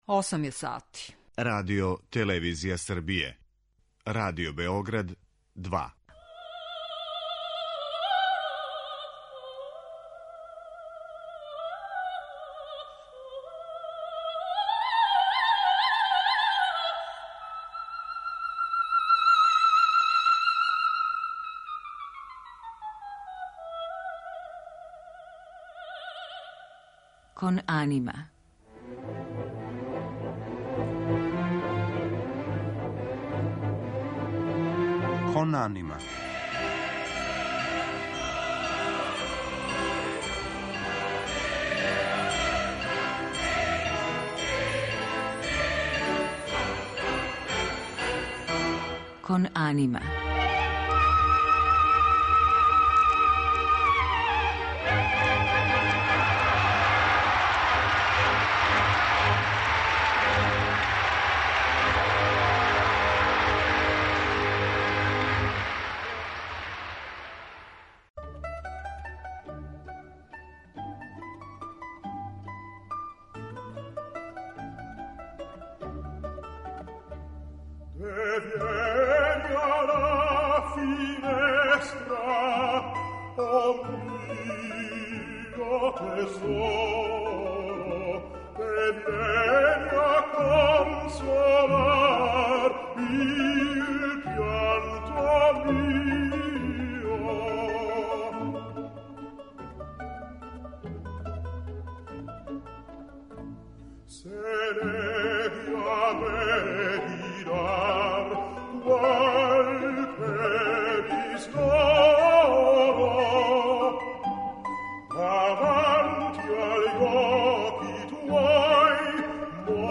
Данашњу емисију Кон анима испуниће оперске нумере које заправо представљају различите вокалне форме, специфично оперске или преузете из традиционалне музике тј. из свакодневног живота.